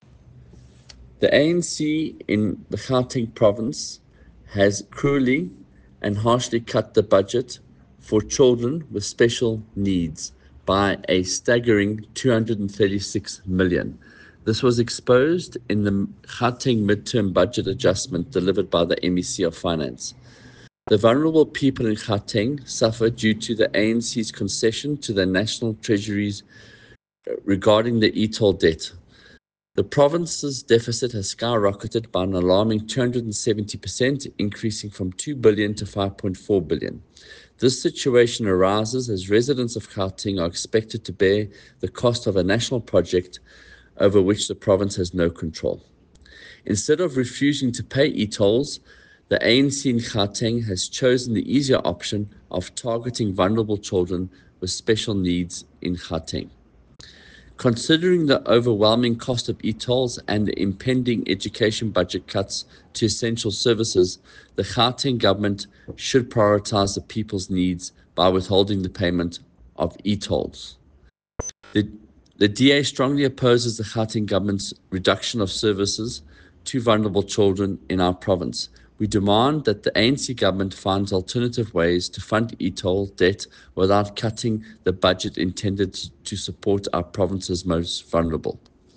soundbite by Michael Waters MPL.